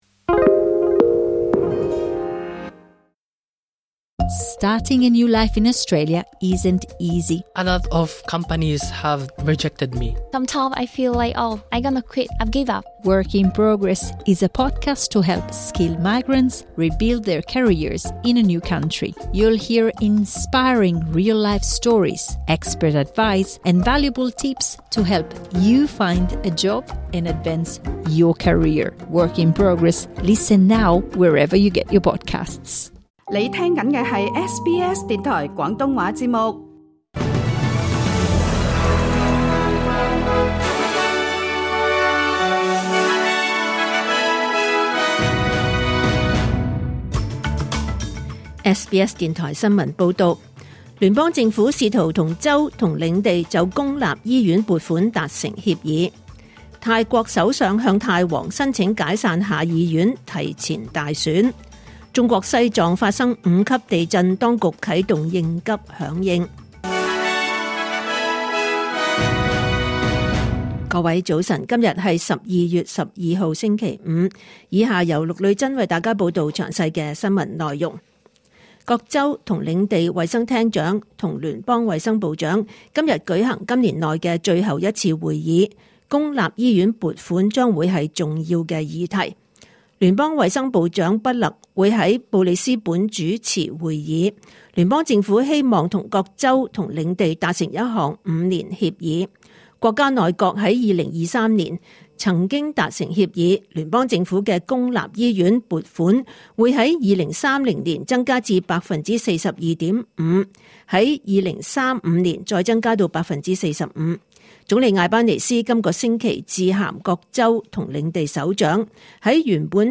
2025年12月12日SBS廣東話節目九點半新聞報道。